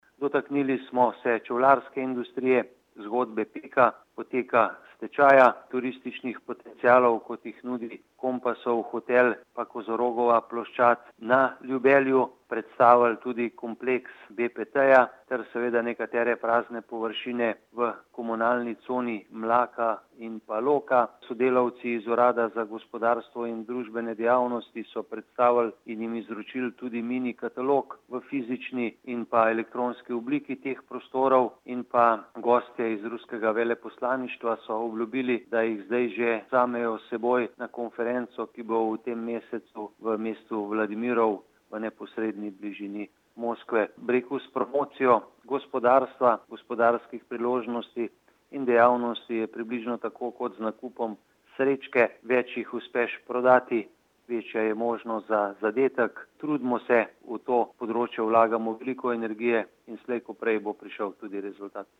izjava_zupanobcinetrzicmag.borutsajovicogospodarskihpriloznostihtrzica.mp3 (1,5MB)